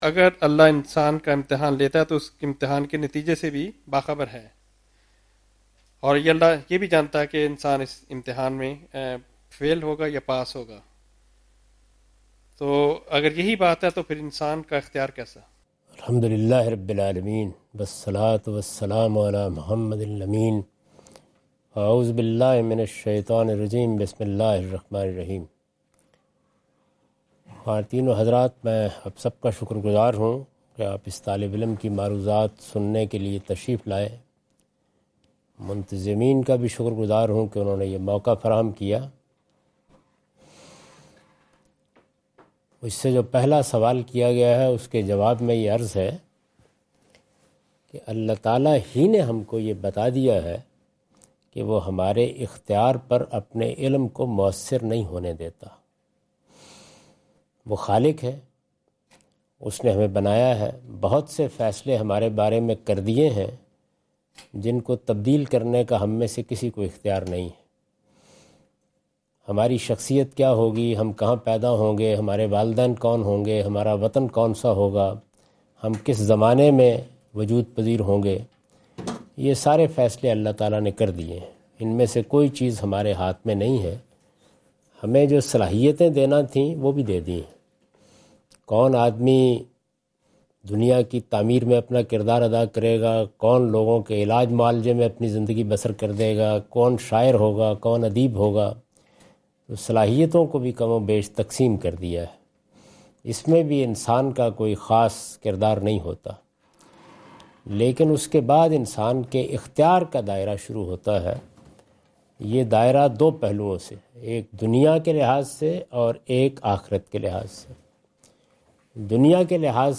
Category: English Subtitled / Questions_Answers /
Javed Ahmad Ghamidi answer the question about "The Knowledge of Allah and Authority of Man" during his Australia visit on 11th October 2015.
جاوید احمد غامدی اپنے دورہ آسٹریلیا کے دوران ایڈیلیڈ میں "اللہ کا علم اور انسان کا اختیار" سے متعلق ایک سوال کا جواب دے رہے ہیں۔